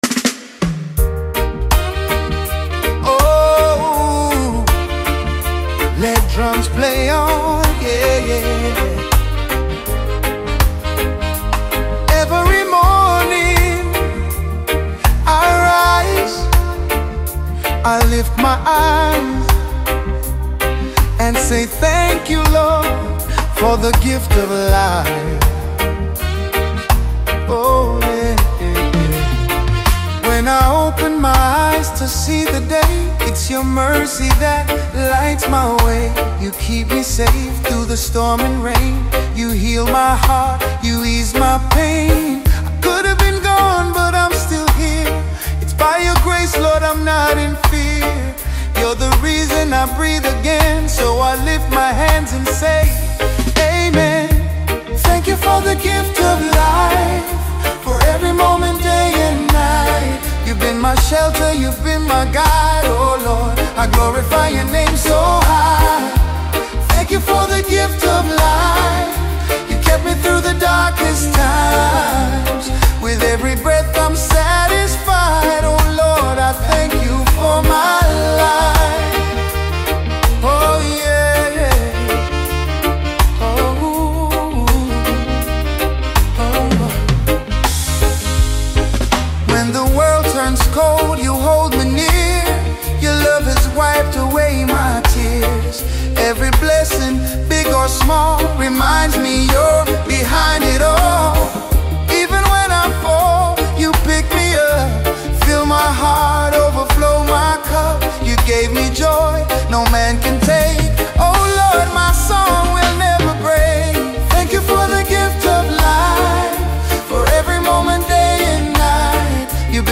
The song carries a peaceful feeling that settles in quietly.